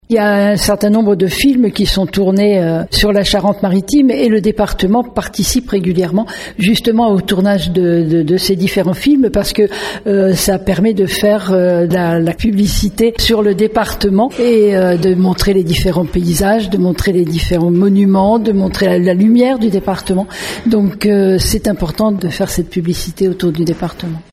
Catherine Desprez, maire de Surgères et 1ère vice-président du Département, nous en parle :